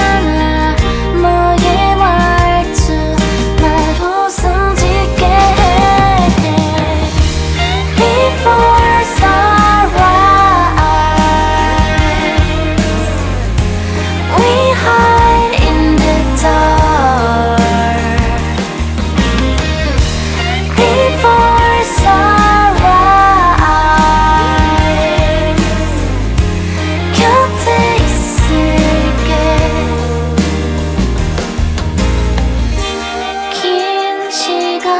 Жанр: Музыка из фильмов / Саундтреки